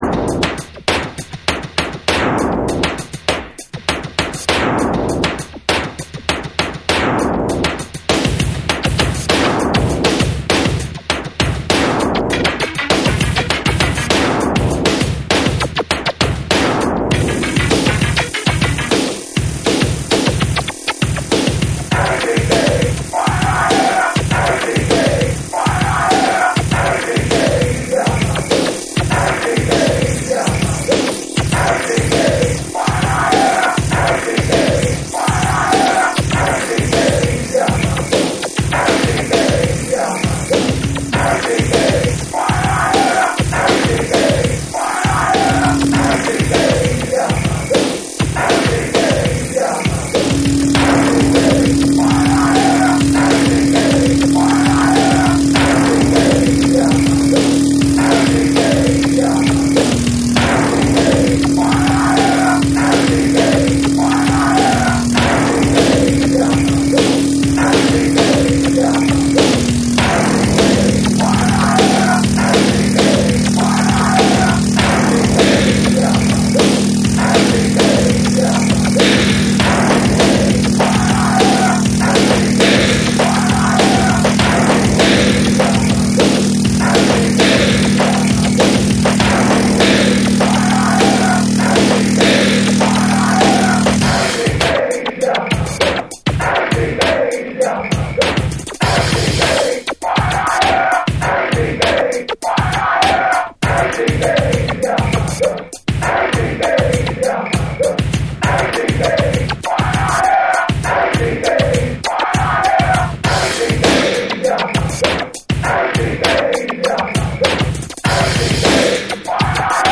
ファンク〜ダブ〜ブレイクビーツが交わる、エッジを効かせたハードエッジなインダストリアル・エレクトロ！
BREAKBEATS